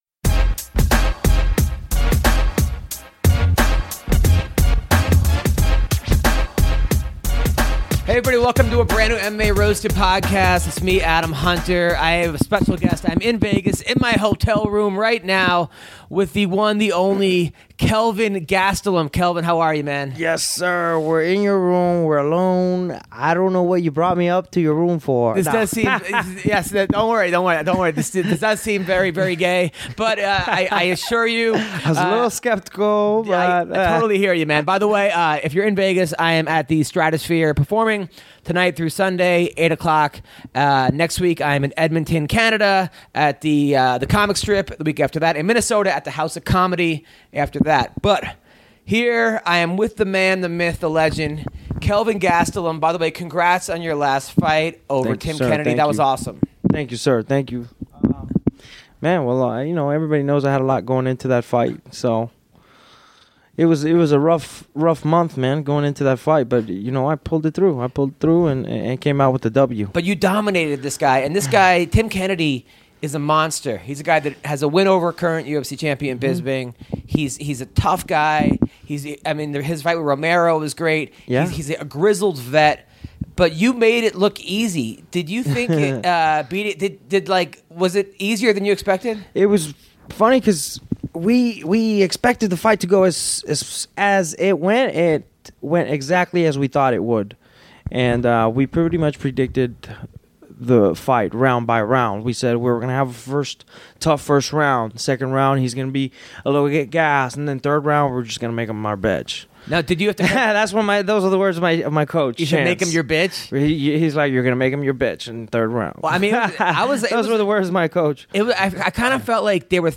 AUDIO WARNING slight popping sounds will be heard through the duration of this episode due to a malfunction with the recording device.